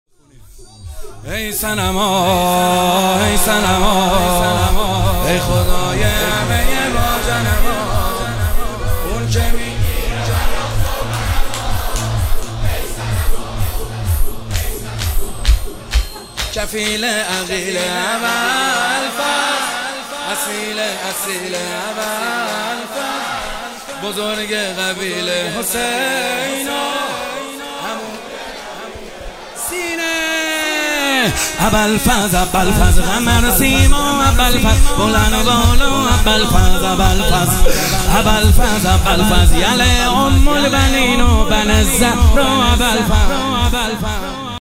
کربلایی محمدحسین حدادیان
شب سوم فاطمیه دوم 1402
شب سوم فاطمیه دوم صوتی شور ای صنما محمد حسین حدادیان